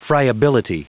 Prononciation du mot friability en anglais (fichier audio)
Prononciation du mot : friability